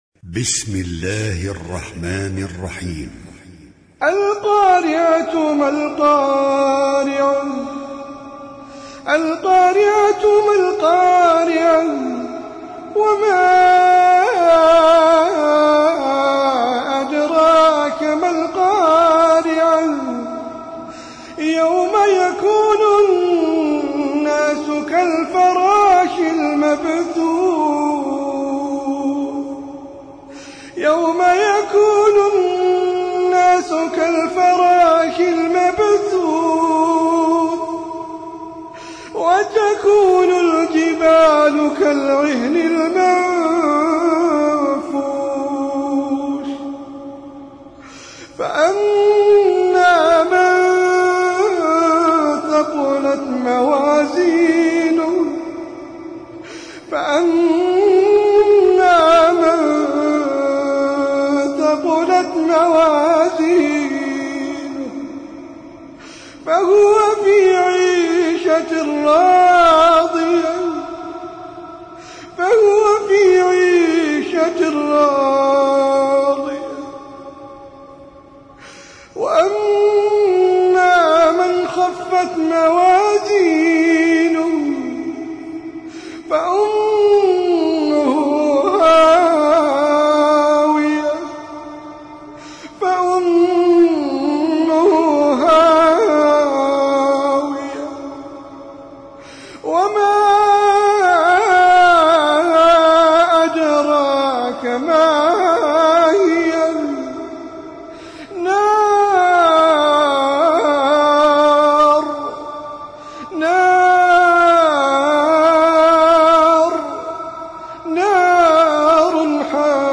Sûrat Al-Qari - Al-Mus'haf Al-Murattal (Narrated by Hafs from 'Aasem)